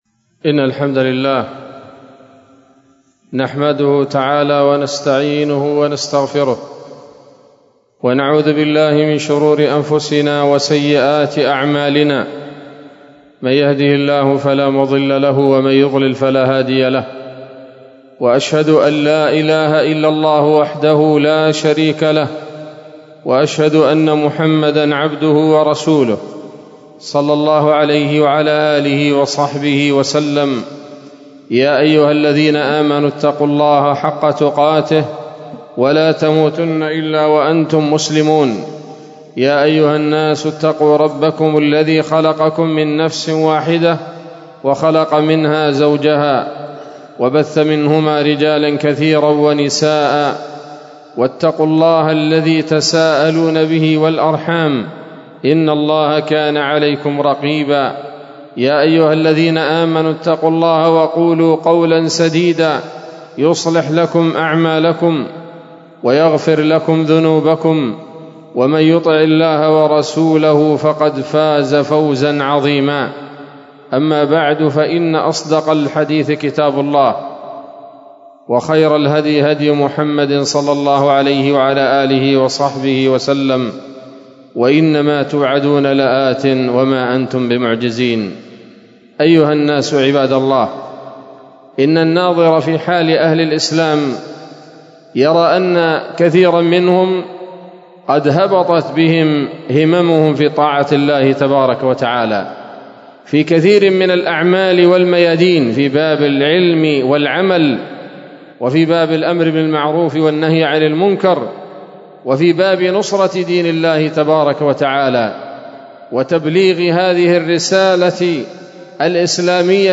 خطبة جمعة بعنوان: (( أسباب انحطاط الهمم )) 15 شعبان 1446 هـ، دار الحديث السلفية بصلاح الدين